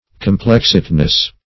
Search Result for " complexedness" : The Collaborative International Dictionary of English v.0.48: Complexedness \Com*plex"ed*ness\ (k[o^]m*pl[e^]ks"[e^]d*n[e^]s), n. The quality or state of being complex or involved; complication.
complexedness.mp3